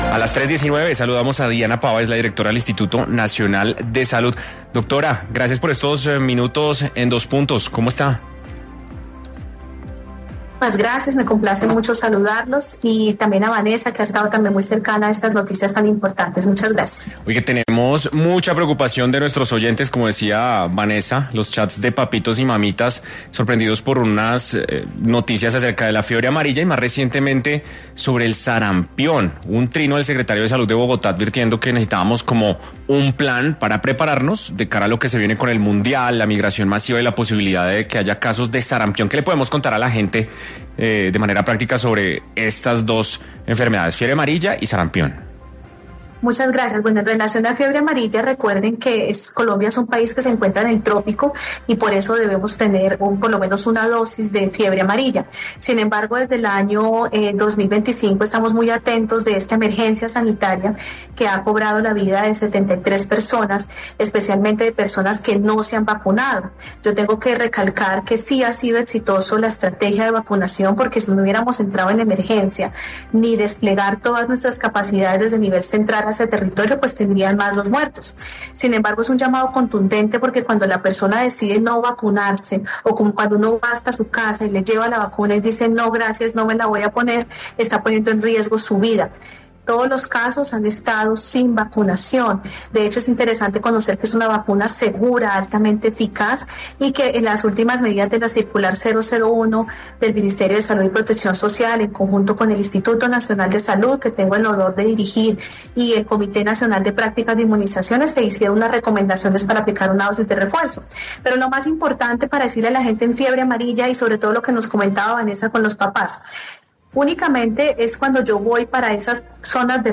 Diana Pava, directora del Instituto Nacional de Salud, conversó con Dos Puntos, de Caracol Radio, para hablar sobre la fiebre amarilla y la alerta que emitieron las autoridades de la salud para motivar la vacunación contra esa enfermedad, especialmente para las personas que viajan a zonas de alto riesgo.